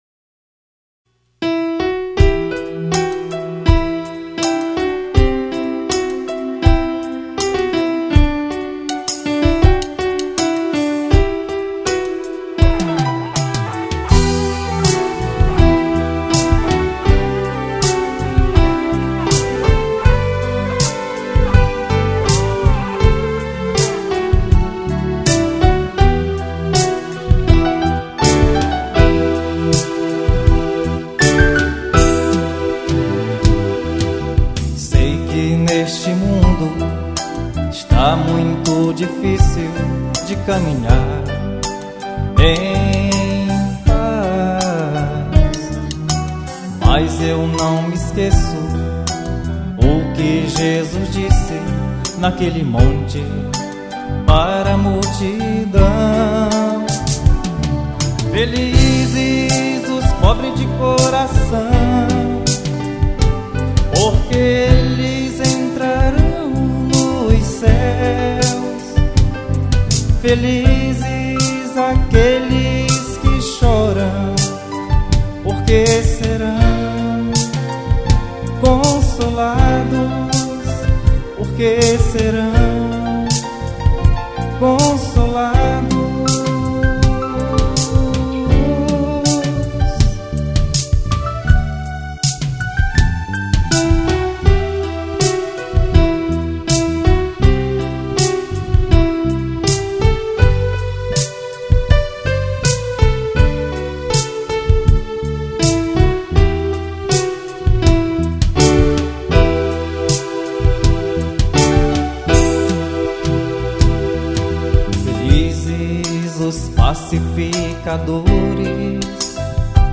Guitarras base/efeitos, baixo e solo